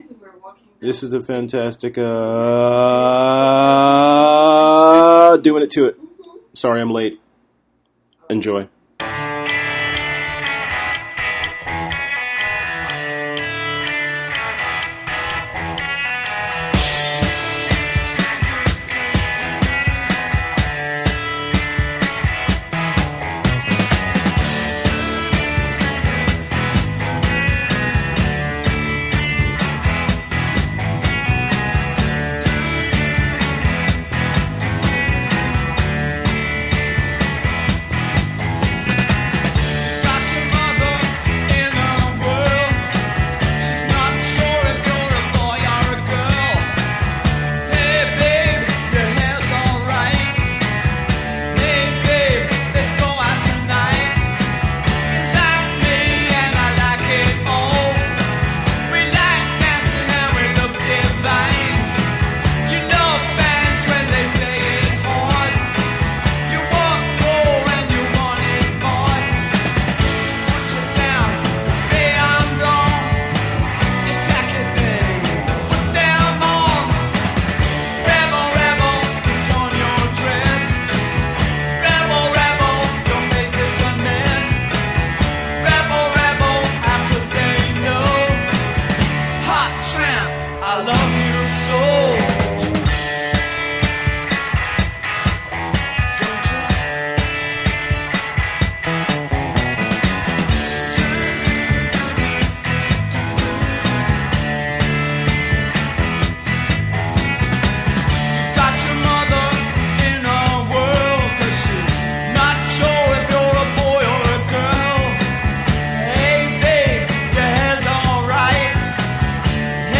Every third Wednesday of the month I will be playing some of my old favorites and new discoveries music wise. Additionally I’ll be interviewing various visual artists, musicians, writers, cartoonists, animators, film makers, doctors, teachers, the guy at the 7-11, anyone who wants to talk about what they’re up to and what they love (or don’t) about this little stretch of life we’ve been afforded.